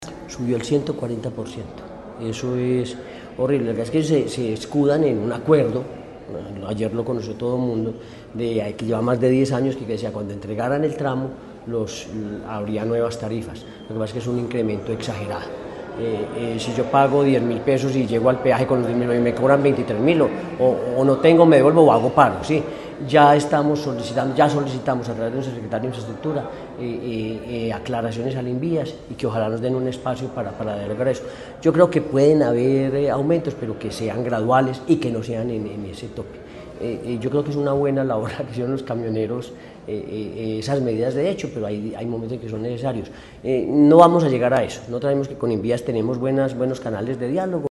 Gobernador de Caldas, Henry Gutiérrez Ángel.